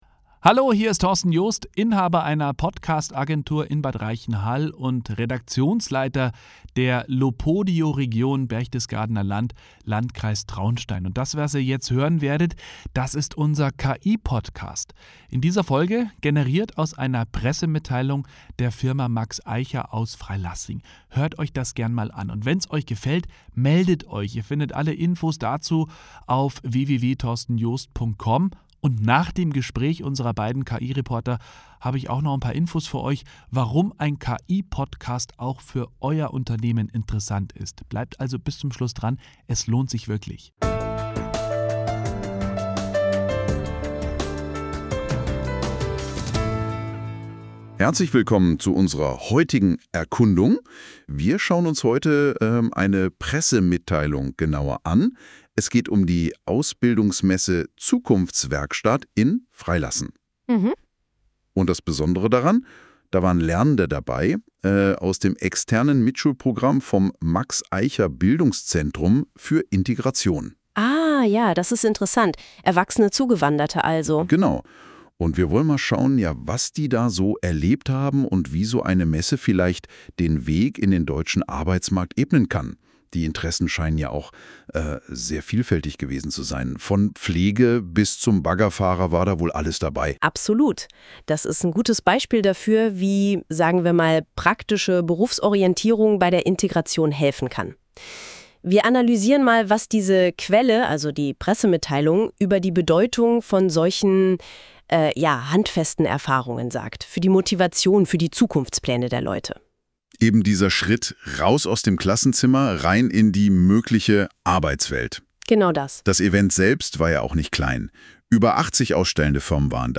Passend zu unserem Pressebericht "Zahnarzt, Klinik oder Baggerfahrer" gibt es zur Zukunftswerkstatt auch ein KI-Interview, ja Sie haben richtig gehört ein Interview der Künstlichen Intelligenz.
KI-Interview11.84 MB